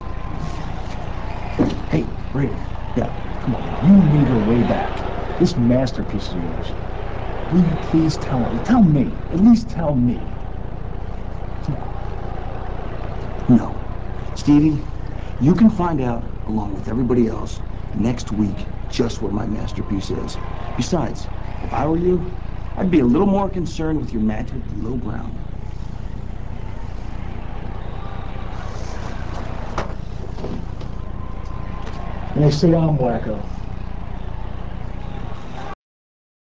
- This clip comes from WWE HEAT - [11.03.02]. Raven is intercepted by Steven Richards in the back and asks to know what Raven's masterpiece is based on their past bond. Raven tells Stevie all will be revealed next week.